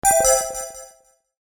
クリック メニュー終了 02
ピココ